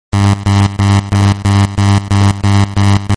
Download Klaxon sound effect for free.
Klaxon